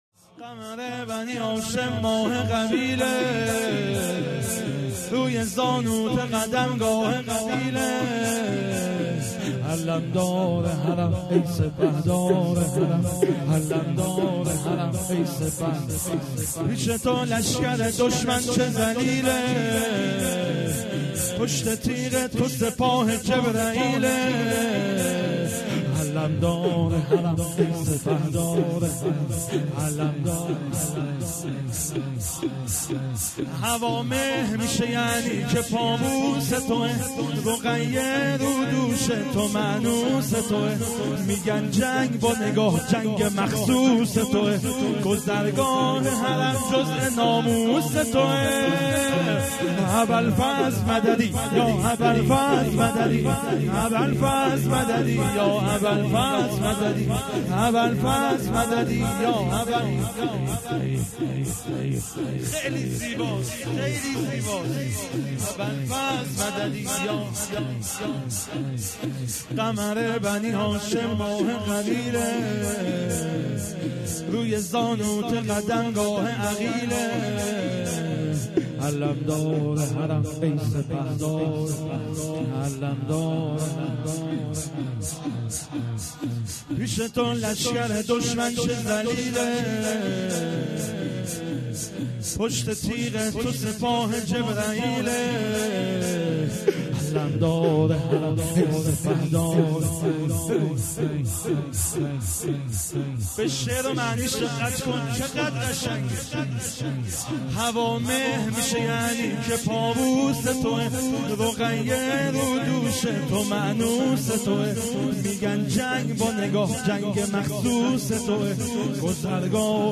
شور ؛ ماه قبیله
هیئت احرار الحسن گرمسار